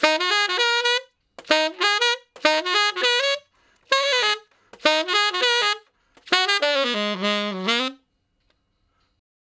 068 Ten Sax Straight (Ab) 01.wav